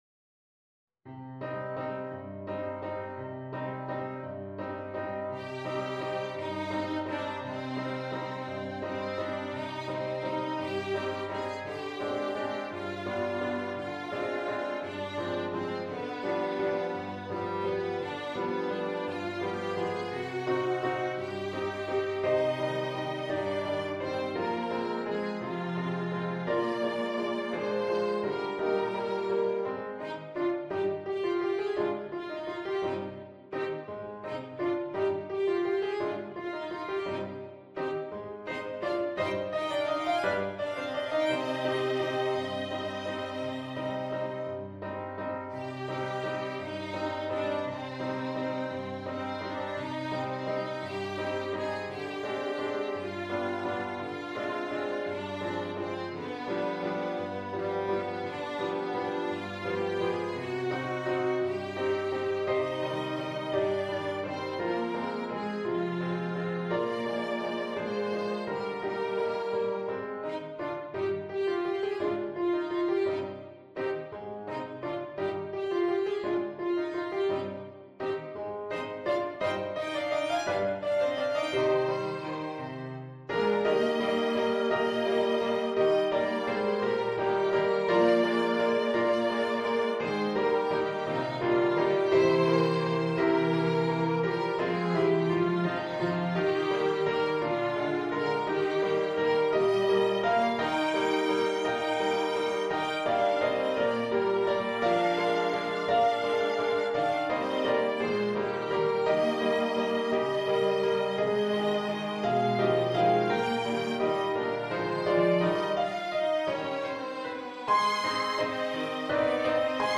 A Haunting Waltz
Adapted for Piano Trio